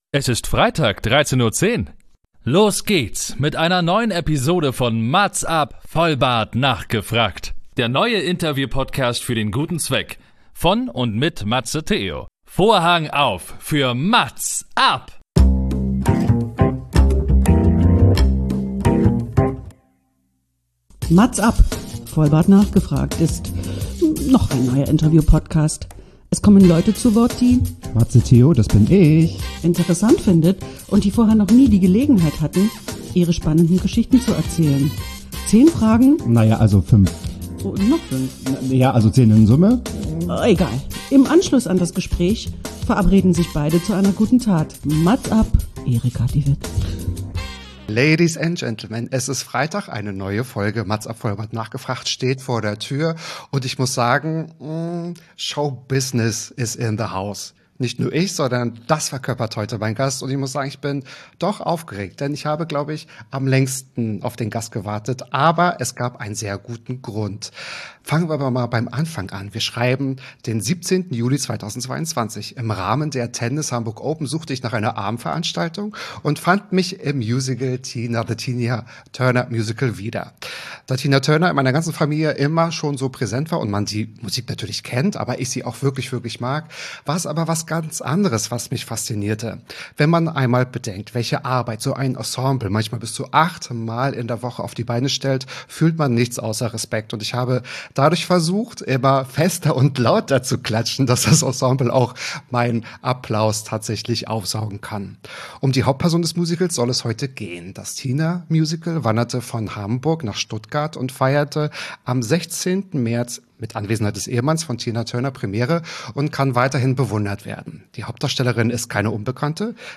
MAZZ AB - der Interview-Podcast mit den einzigartigen Fragen, der jeden 13. Tag des Monats erscheint. Hier stelle ich ungewöhnliche Fragen an faszinierende Persönlichkeiten aus verschiedenen Bereichen.